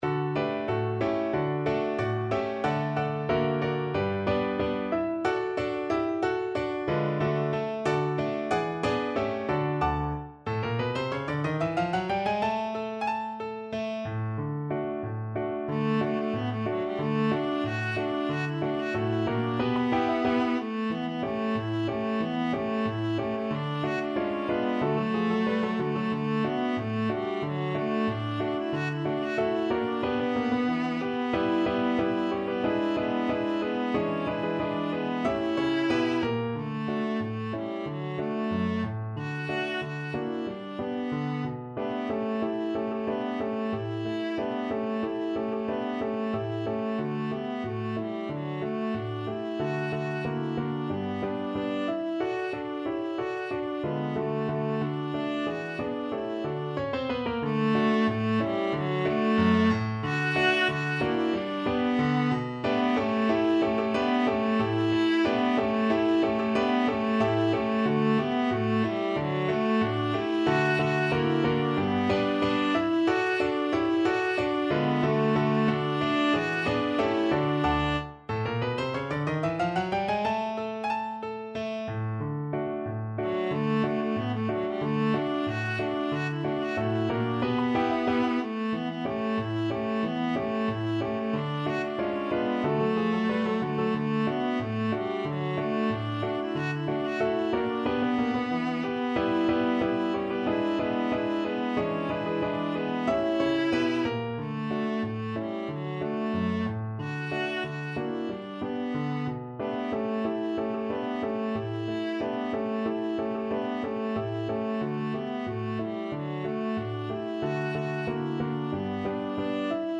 2/4 (View more 2/4 Music)
Allegretto =92
Traditional (View more Traditional Viola Music)